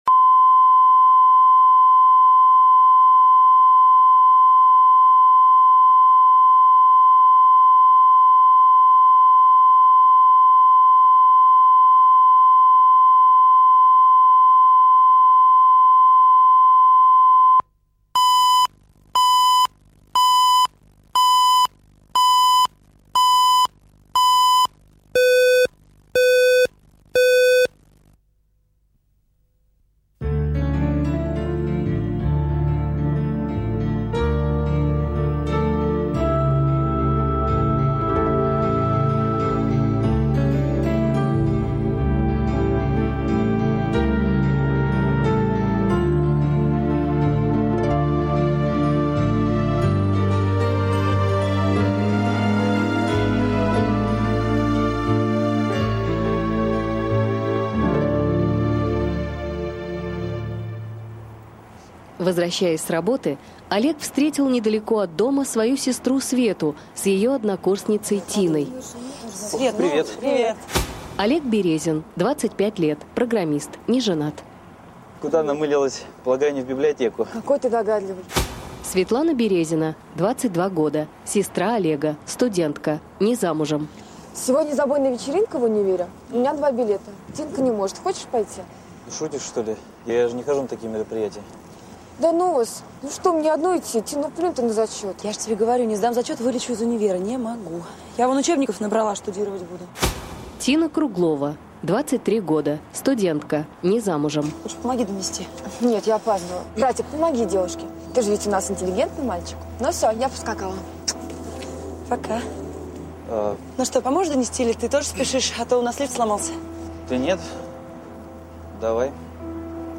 Аудиокнига Зигзаг любви | Библиотека аудиокниг
Прослушать и бесплатно скачать фрагмент аудиокниги